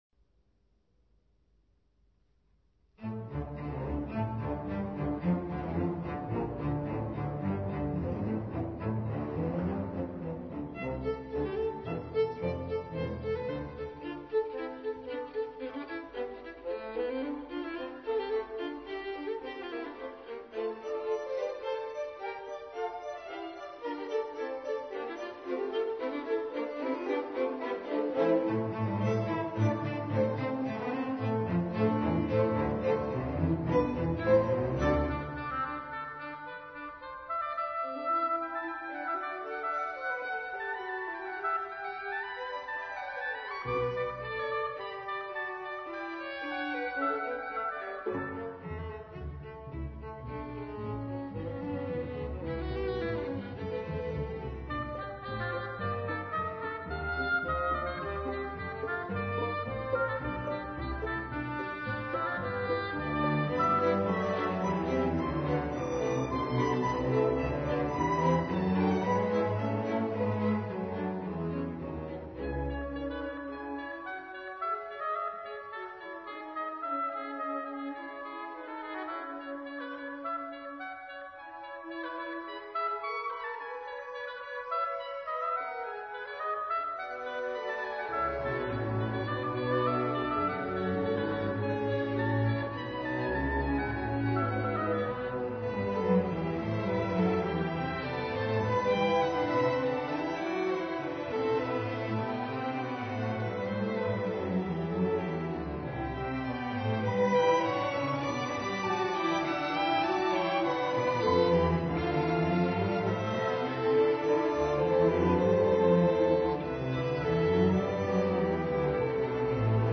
MP3 Stereo » Download audio file 102 letöltés 344 plays